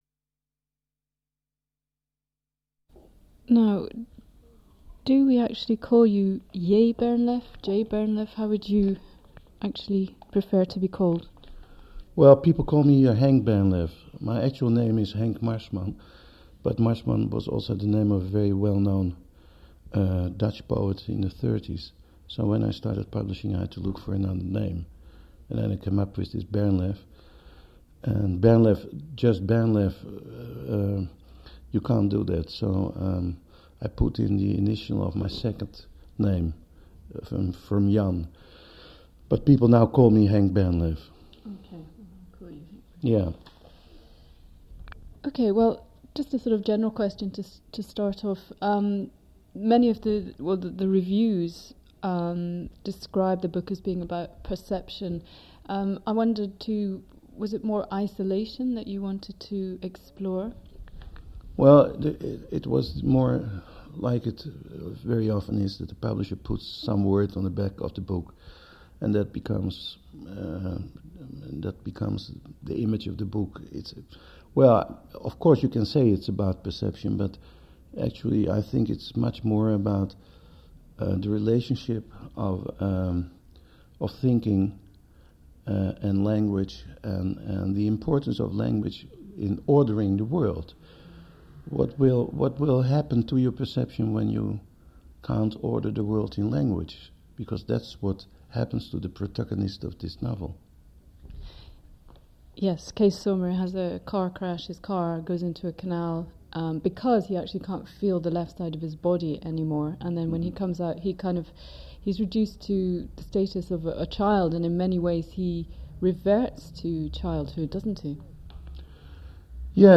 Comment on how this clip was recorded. In this unedited interview